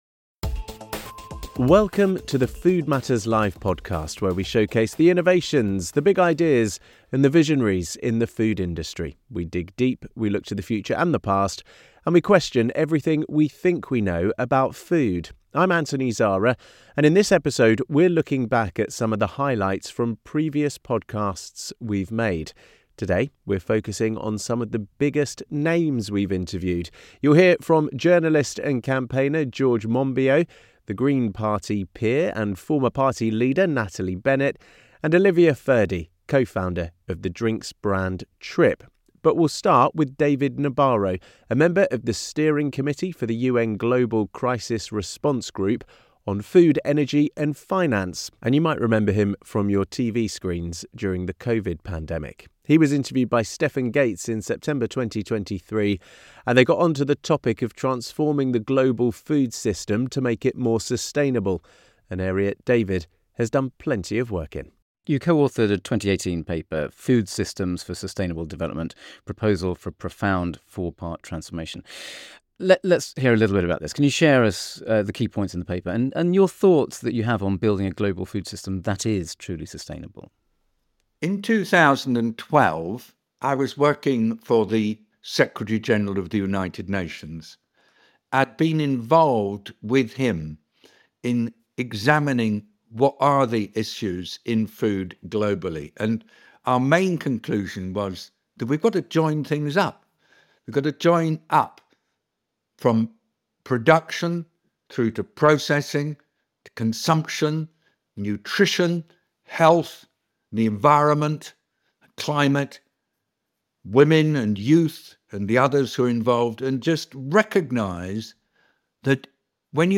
In this episode we look back at some of the highlights from previous podcasts we have made, focussing on some of the biggest names we have interviewed.